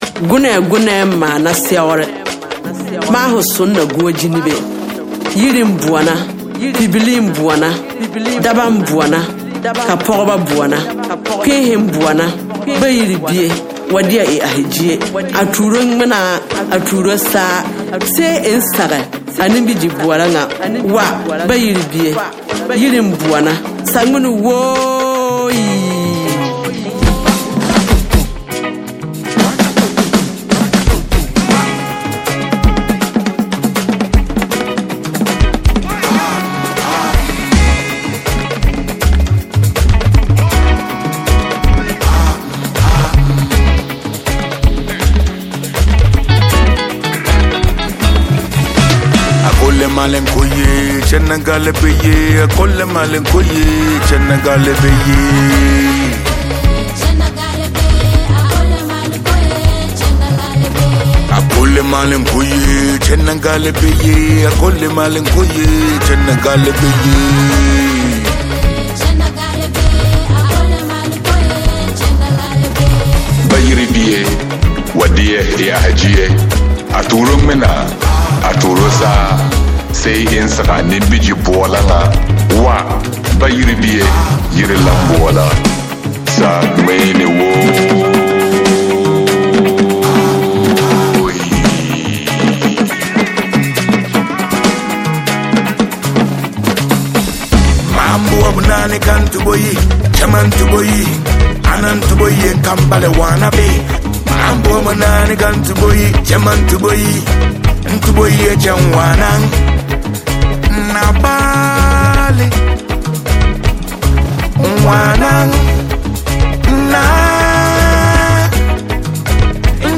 • Genre: Reggae / Dancehall / Afrobeat